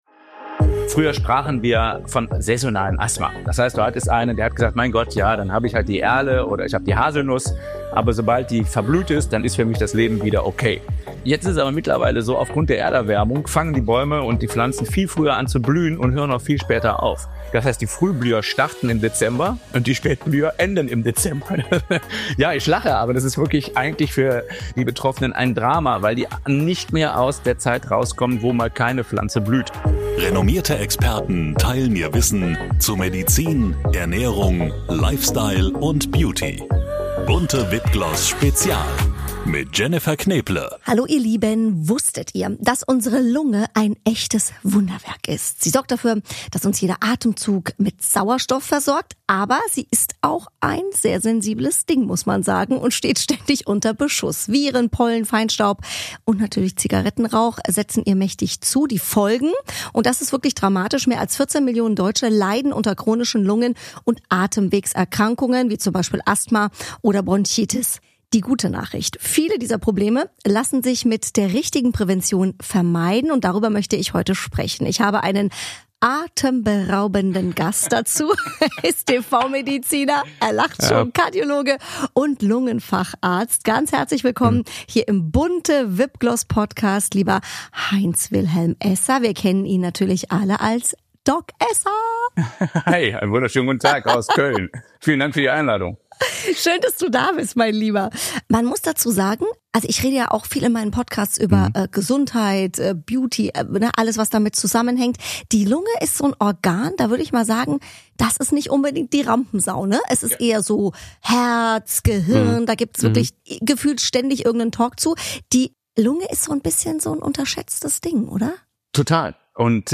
In dieser Folge BUNTE VIP GLOSS spricht Podcast-Host Jennifer Knäble mit Doc Esser über die unterschätzte Bedeutung der Lunge und darüber, wie wir sie langfristig gesund halten können.